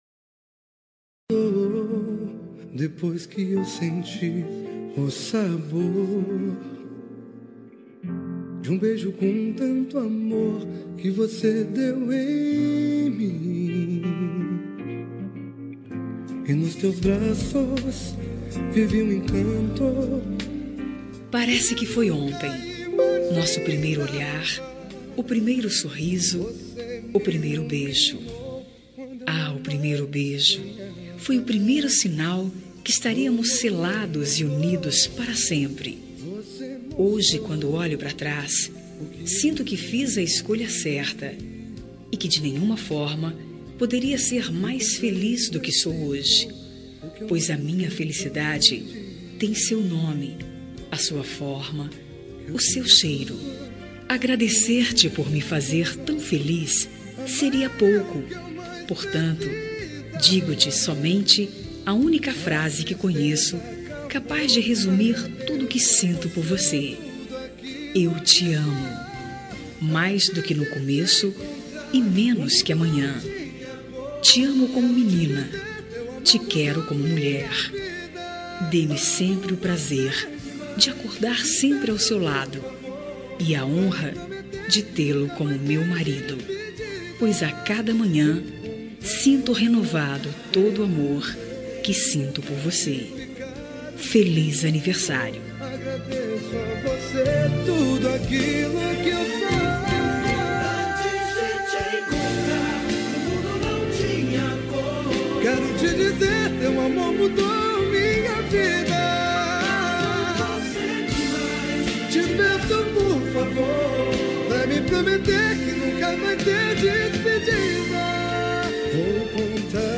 Telemensagem de Aniversário de Casamento Romântico – Voz Feminina – Cód: 4227